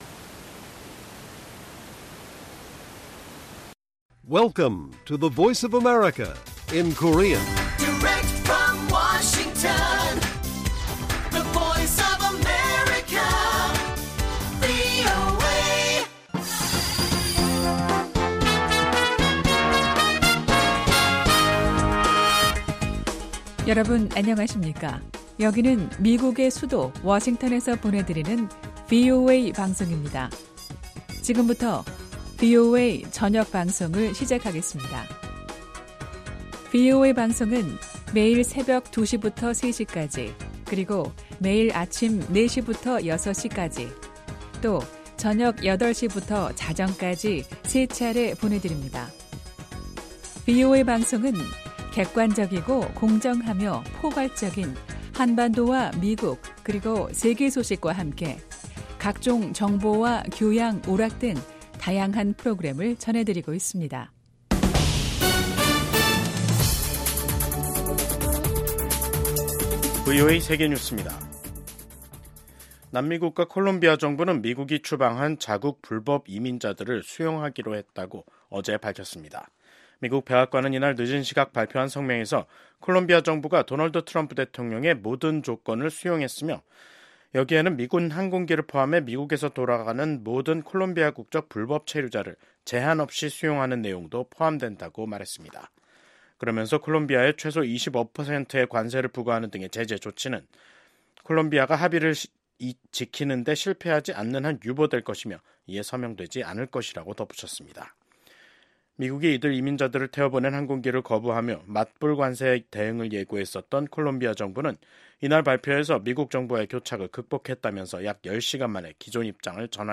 VOA 한국어 간판 뉴스 프로그램 '뉴스 투데이', 2025년 1월 27일 1부 방송입니다. 북한은 김정은 국무위원장이 참관한 가운데 서해상으로 전략순항미사일을 시험 발사했습니다. 미국 하원에서 미한 동맹의 중요성을 재확인하는 결의안이 발의됐습니다. 영국 국방부는 러시아에 파병된 북한군의 3분의 1 이상이 사망하거나 부상한 것으로 추정했습니다.